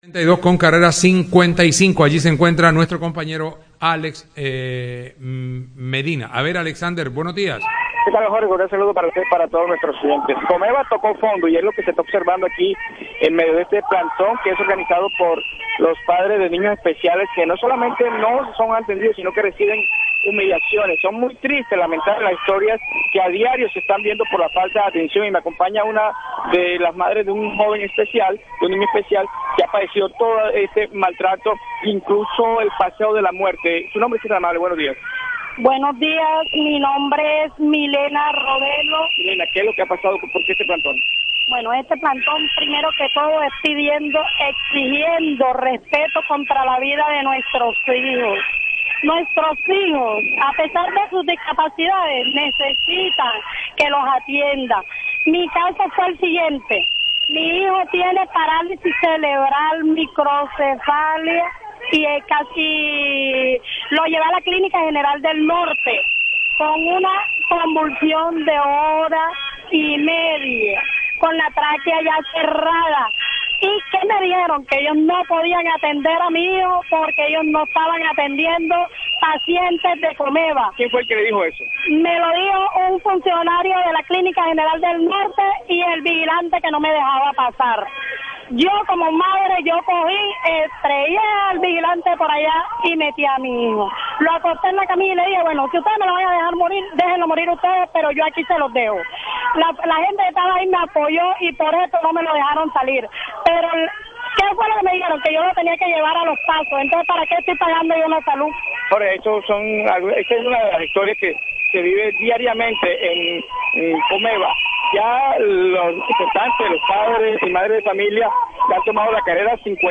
Los padres realizaron un plantón en las afueras del edificio Ejecutivo 2, en la carrera 55 con calle 72, con pitos, pancartas y arengas.